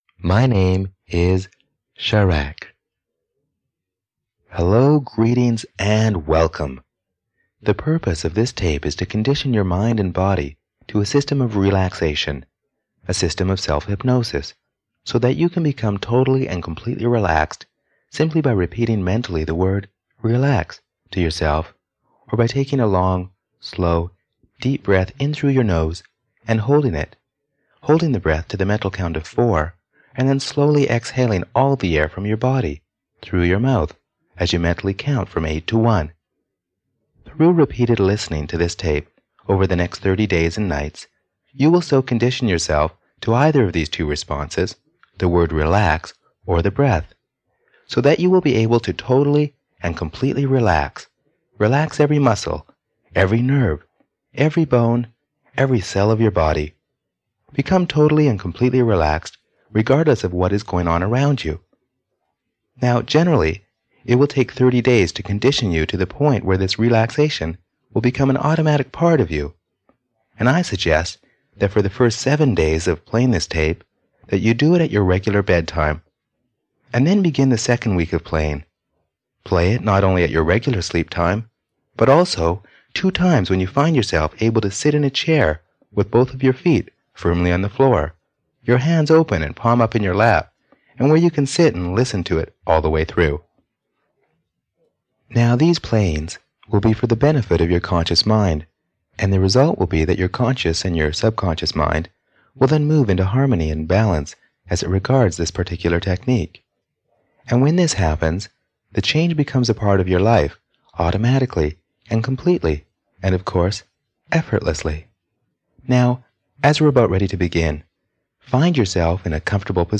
Guided Relaxation (EN) audiokniha
Ukázka z knihy